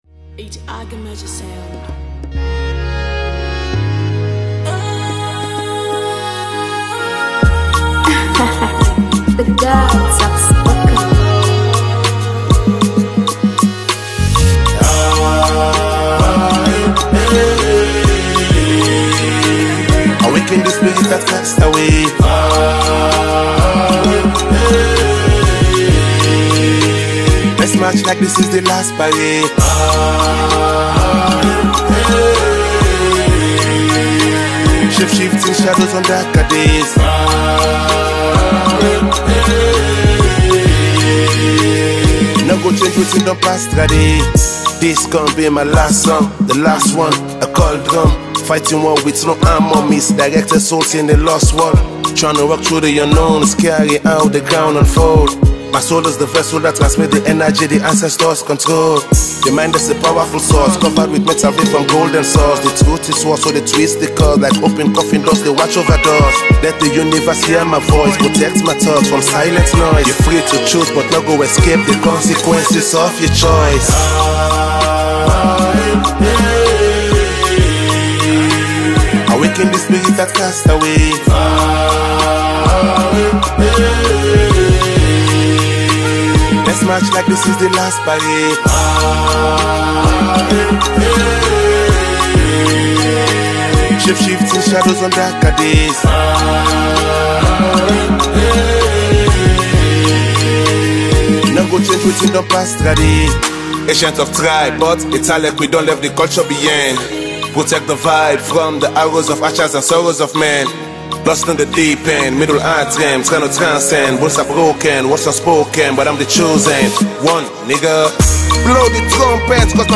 With the appearance of a foreign singer with sweet melodies